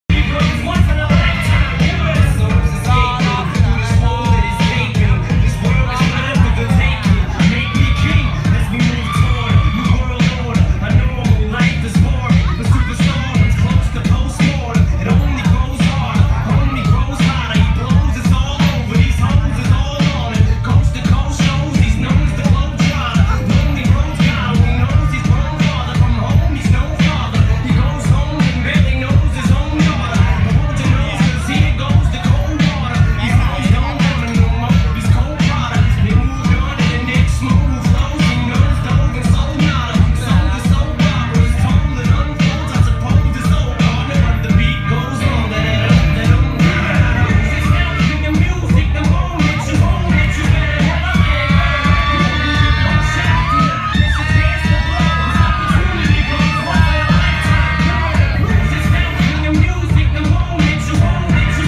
troche stare i znane - Hip-hop / Rap / R'N'B